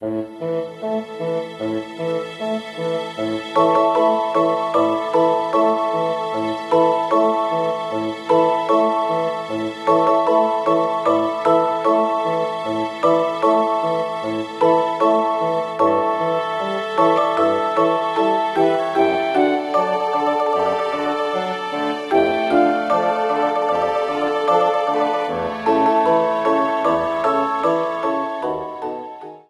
Ripped from the game
applied fade-out